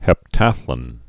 (hĕp-tăthlən, -lŏn)